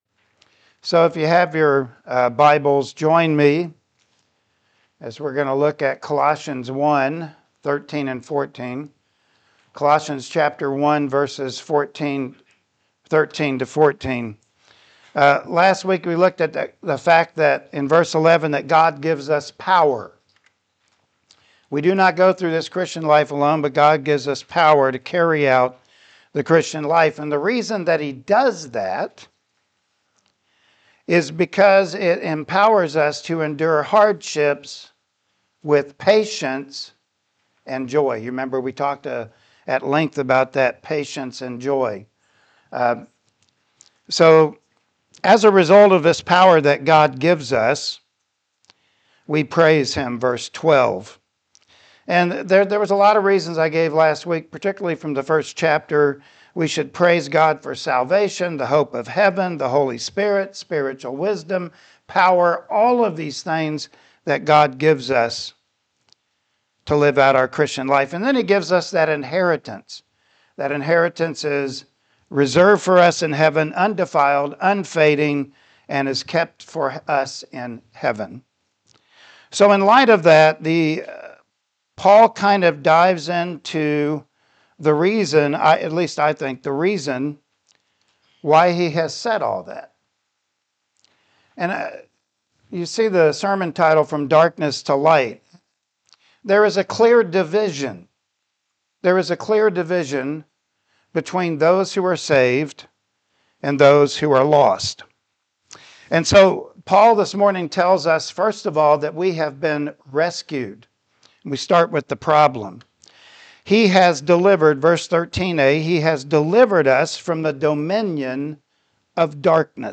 Colossians 1:13-14 Service Type: Sunday Morning Worship Service Topics: Spiritual Transformation